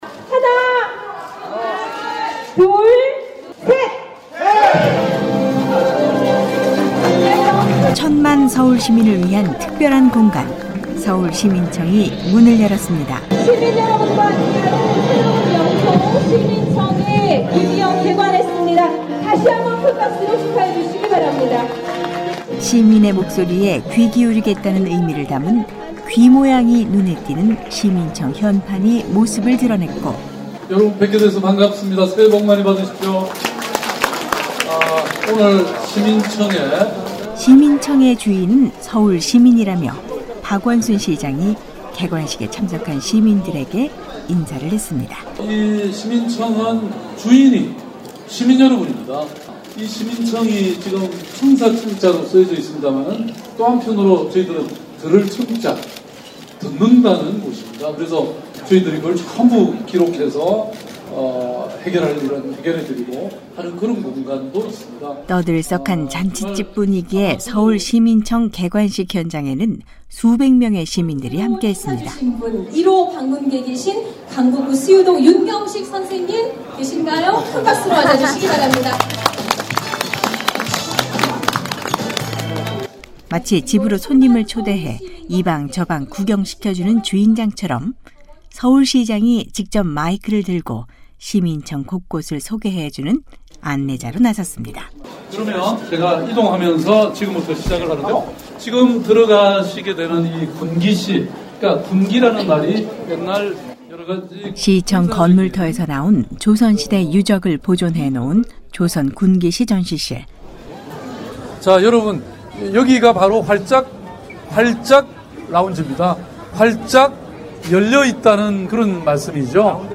오늘은 ‘서울시민청’으로 가보겠습니다.